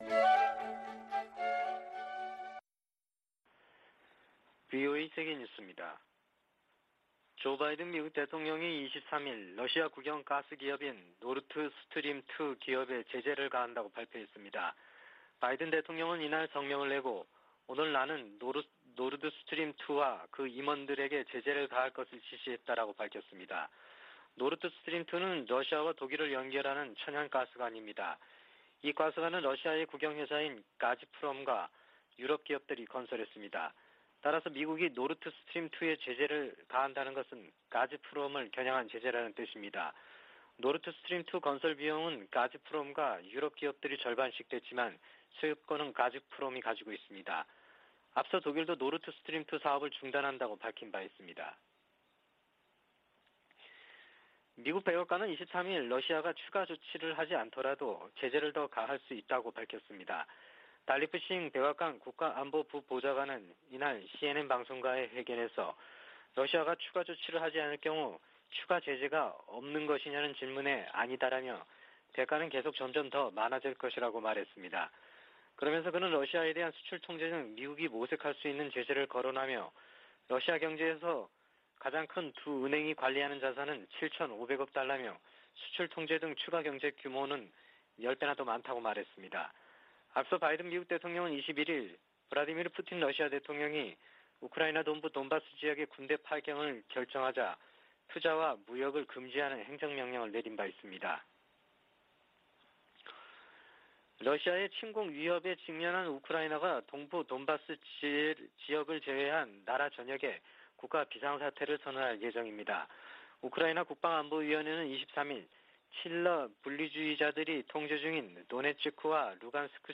VOA 한국어 아침 뉴스 프로그램 '워싱턴 뉴스 광장' 2021년 2월 24일 방송입니다. 최근 미국이 B-52 전략폭격기를 괌에 전개한 것은 인도태평양 역내 공격 억지를 위한 것이라고 기지 당국자가 밝혔습니다. 미국과 일본이 탄도미사일 방어에 초점을 둔 연례 연합훈련에 돌입했습니다. 한국인 70% 이상이 자체 핵무기 개발을 지지하는 것으로 나타났습니다.